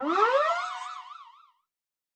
Media:Medic_evo2_dep.wav 部署音效 dep 在角色详情页面点击初级、经典、高手和顶尖形态选项卡触发的音效